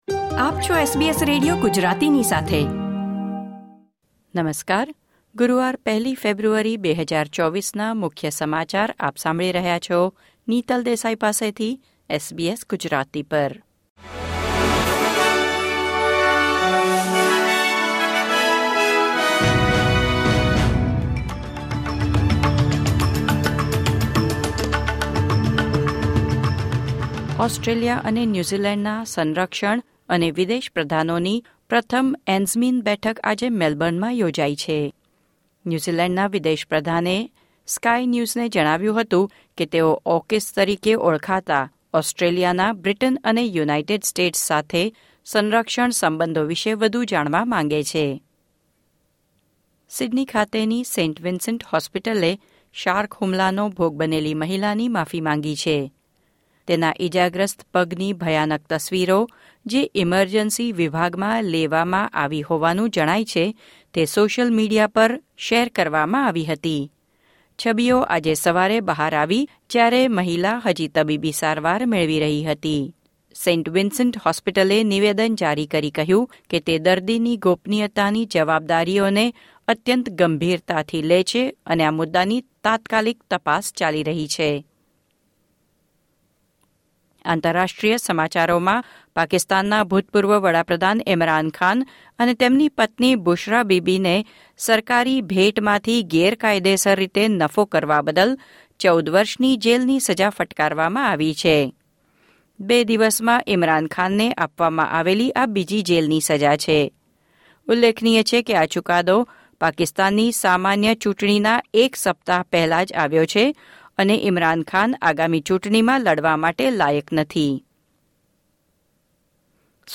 SBS Gujarati News Bulletin 1 February 2024